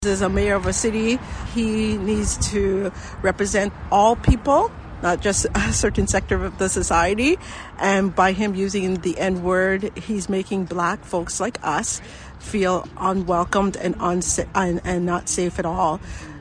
Outside Peterborough City Hall was a bit busier than normal for a regular Monday night meeting.